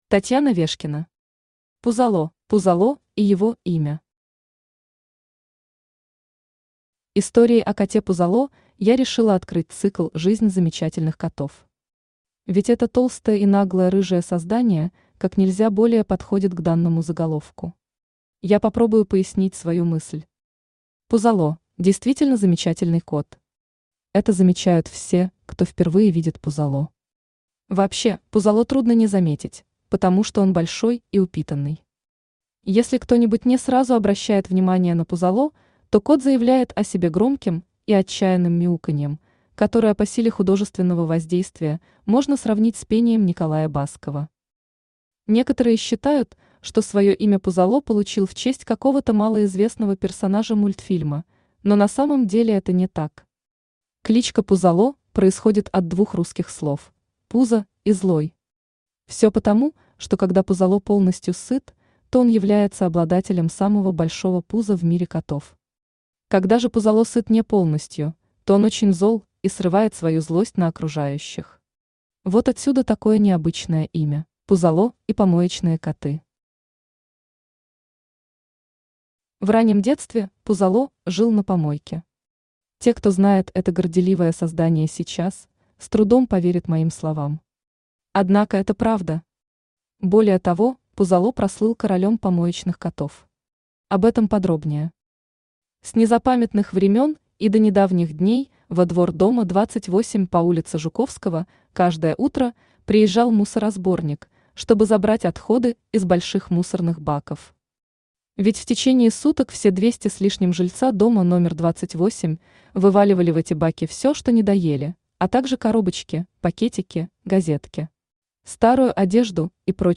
Aудиокнига Пузолло Автор Татьяна Вешкина Читает аудиокнигу Авточтец ЛитРес.